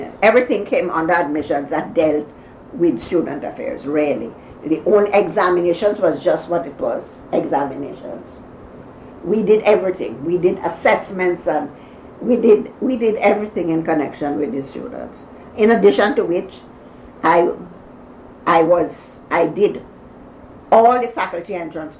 1 audio cassette